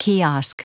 Prononciation du mot kiosk en anglais (fichier audio)
Prononciation du mot : kiosk